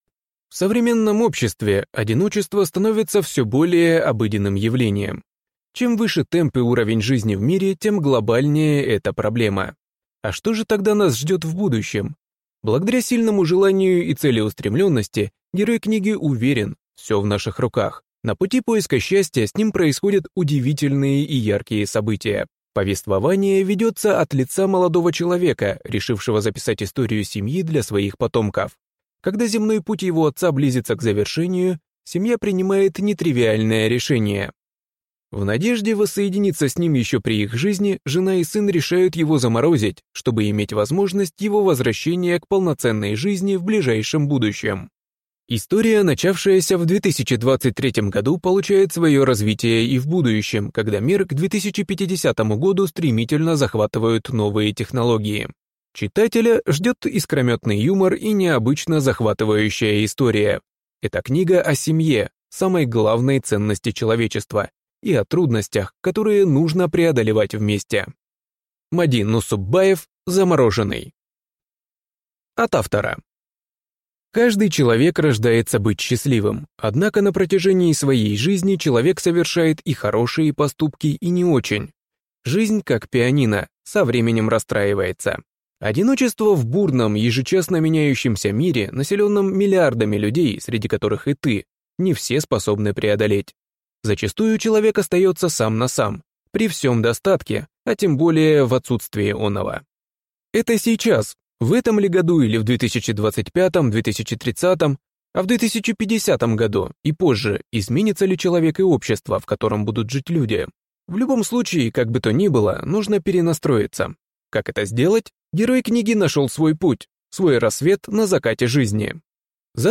Аудиокнига Замороженный | Библиотека аудиокниг